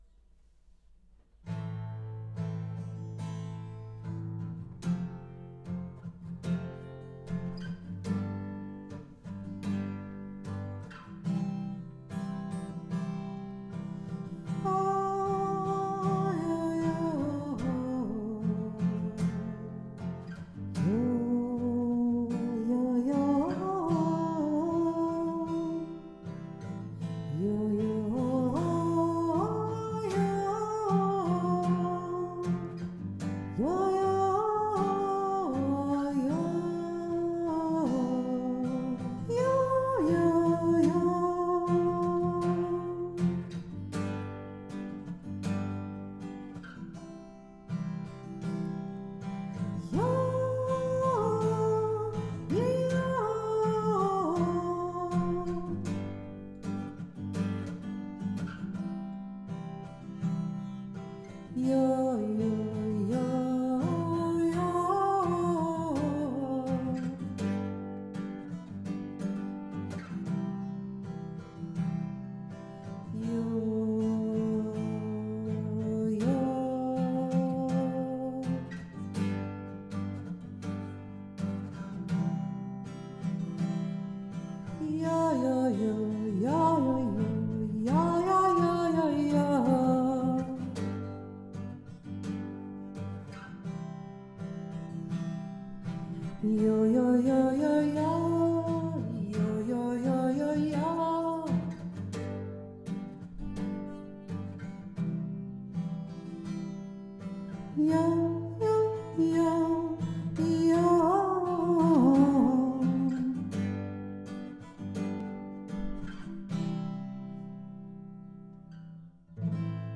Frage - Antwort
Auf Akkorden improvisieren:
Am F G C
frage-antwort-auf-akkorden.mp3